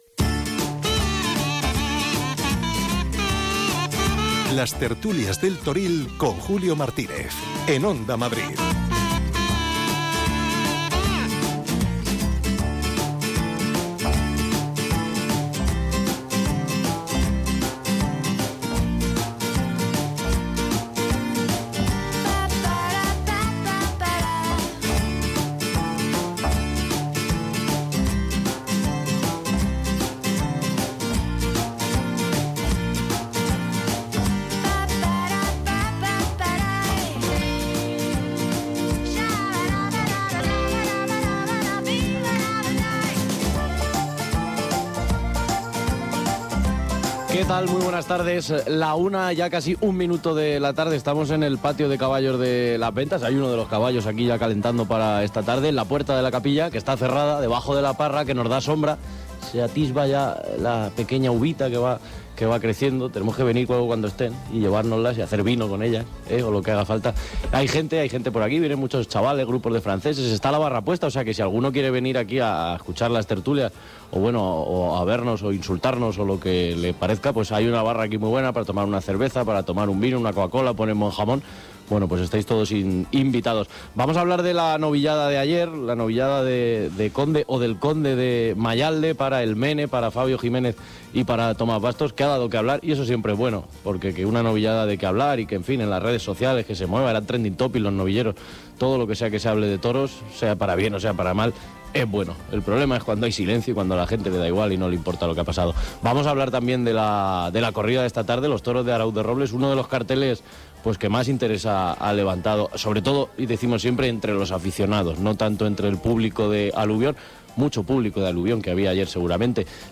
entrevistará a toreros, ganaderos y expertos del sector cada día desde Las Ventas. Una visión distinta de la Feria de San Isidro que todo amante de la tauromaquía no se puede perder.